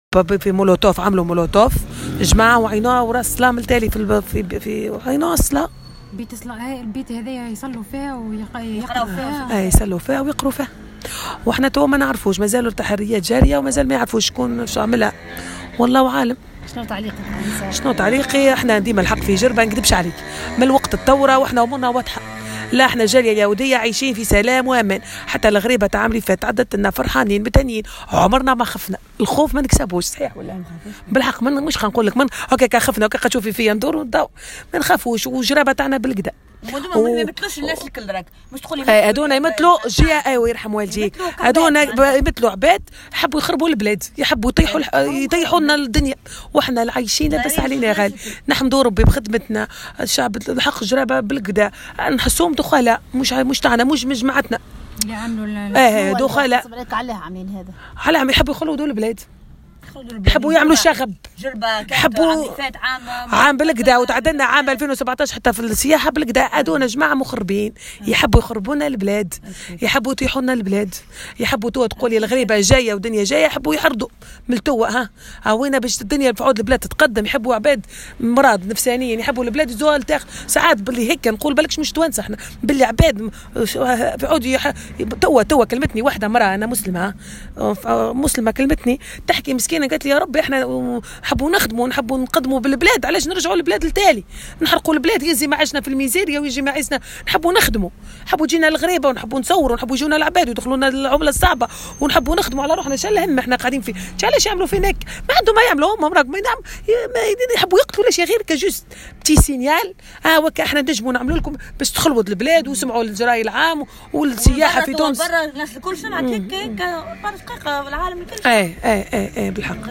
رسالة مواطنة تونسية من الديانة اليهودية إلى المعتدين على بيت صلاة في جربة